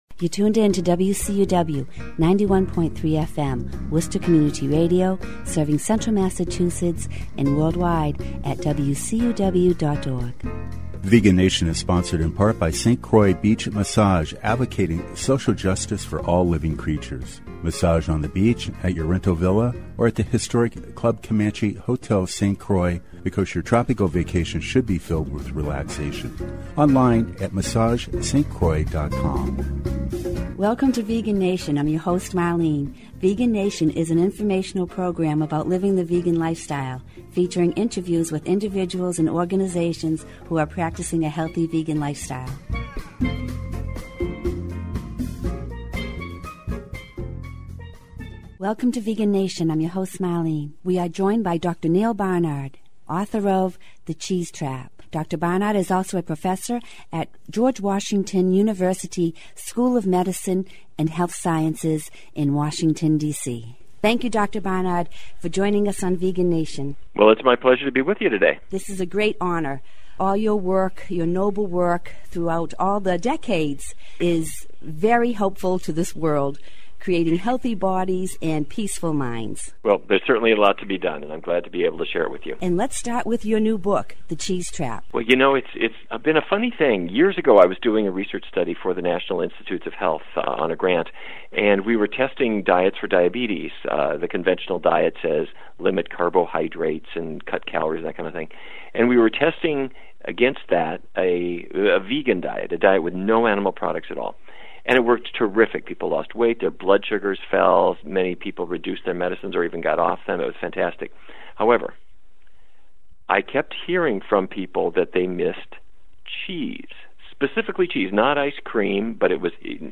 Vegan Nation radio interviews (2017 – 2025) with Dr. Neal Barnard, founder & president of Physicians Committee for Responsible Medicine, and Barnard Medical Center, Washington, D.C.